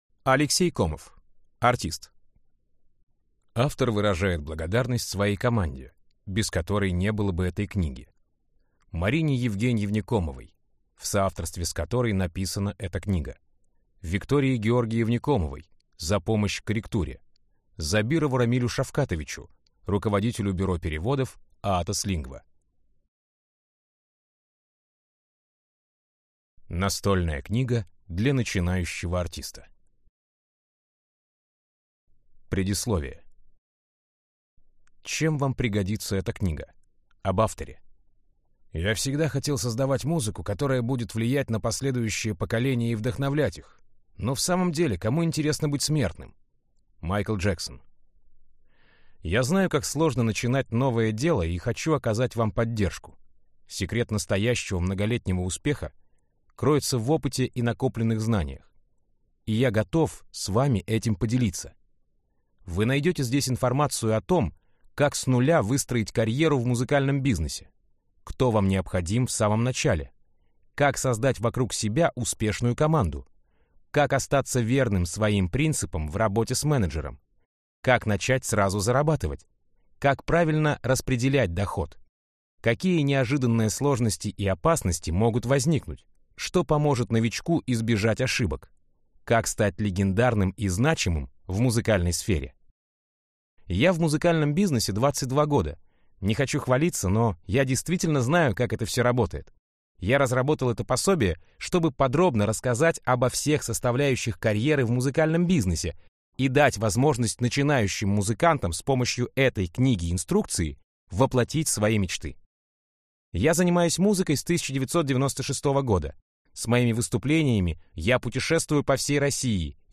Aудиокнига Артист